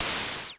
Index of /90_sSampleCDs/300 Drum Machines/Klone Dual-Percussion-Synthesiser/KLONE FILT NW8